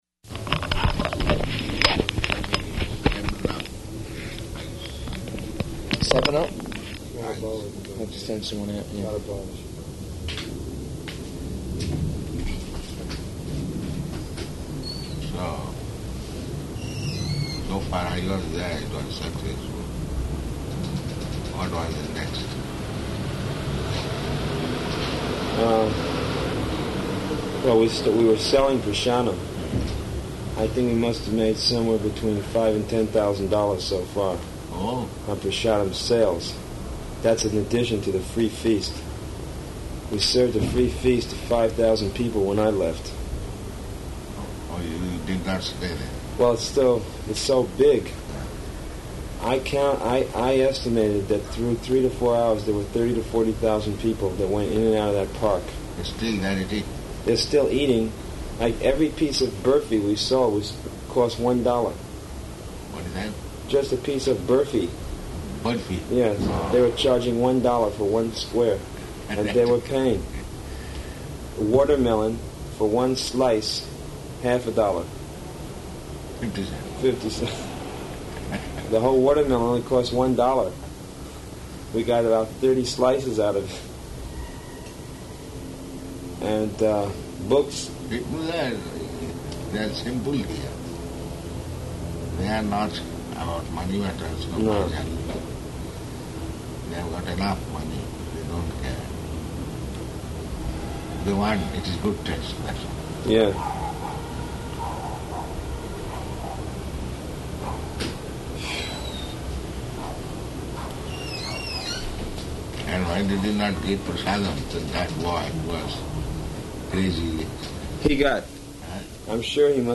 Room Conversation
Room Conversation --:-- --:-- Type: Conversation Dated: July 18th 1976 Location: New York Audio file: 760718R1.NY.mp3 Prabhupāda: ...7-UP with ice.